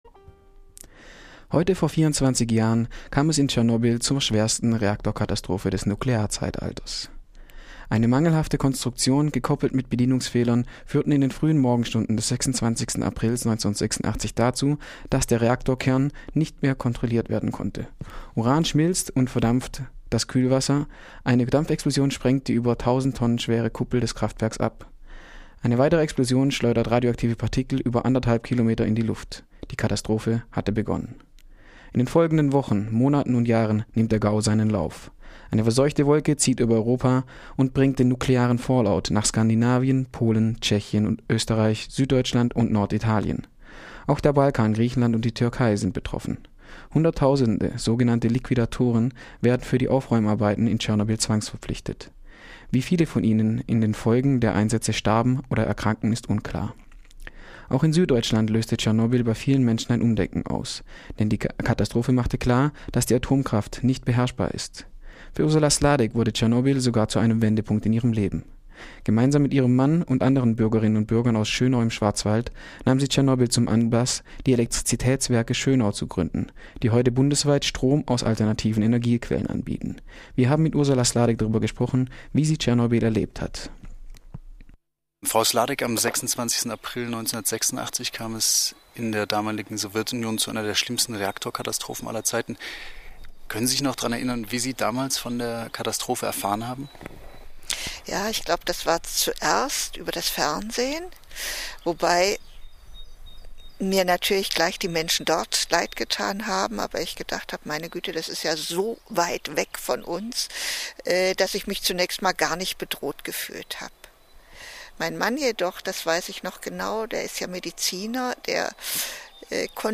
Tschernobyl Jahrestag - Interview